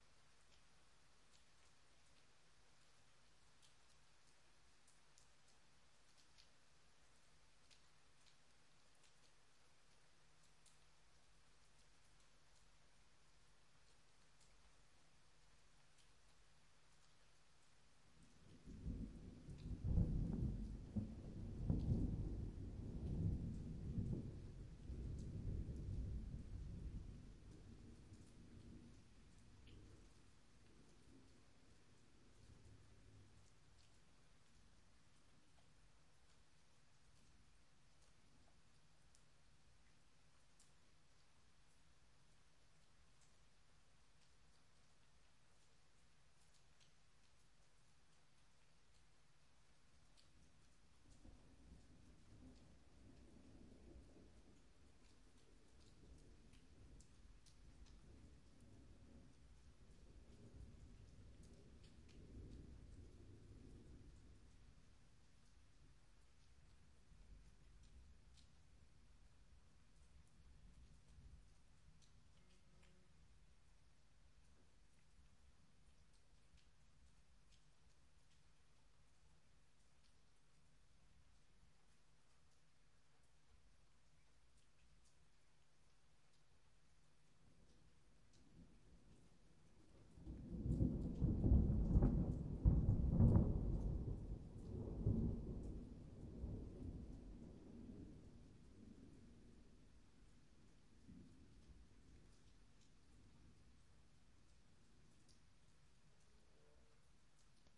雷声长鸣
描述：结合几声雷声，创造出新的长雷声音效。
标签： 雷暴 风暴 雷暴 闪电
声道立体声